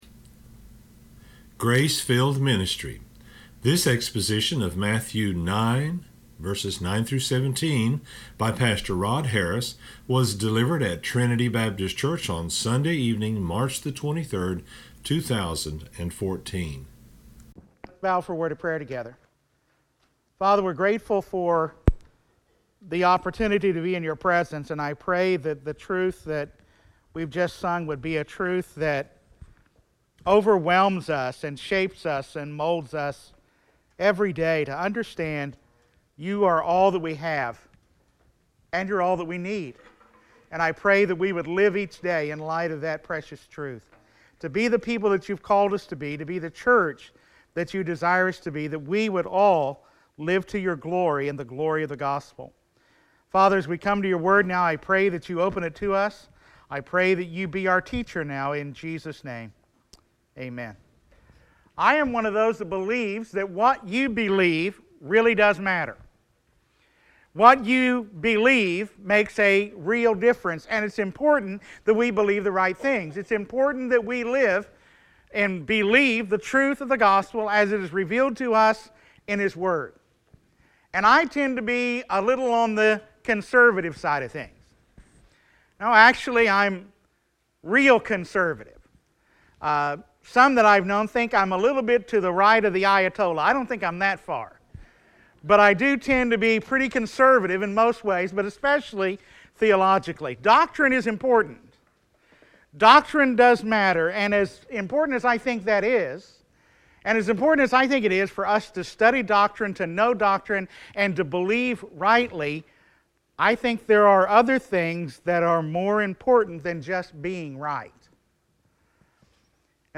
delivered at Trinity Baptist Church on Sunday evening